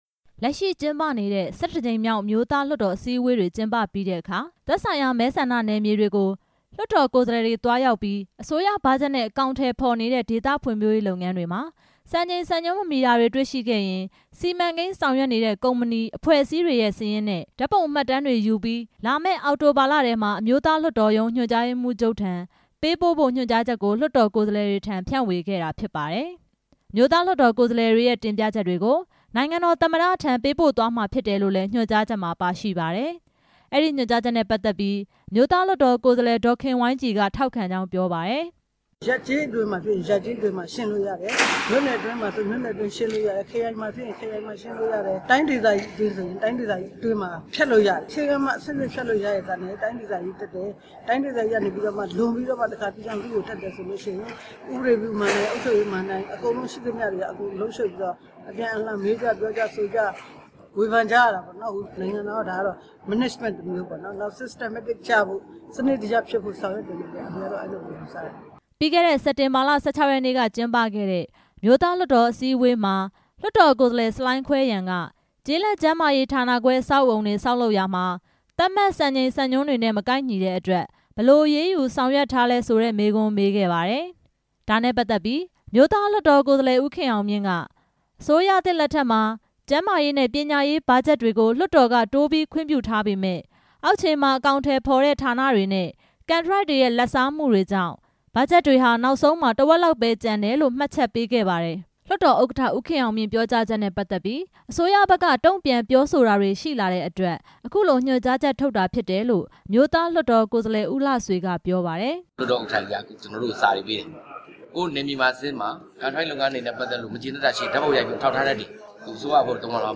အဲ့ဒီညွှန်ကြားချက် ထုတ်ပြန်ခဲ့တာနဲ့ ပတ်သက်ပြီး အမျိုးသားလွှတ်တော် ကိုယ်စားလှယ် ဦးလှဆွေက အခုလိုပြောပါတယ်။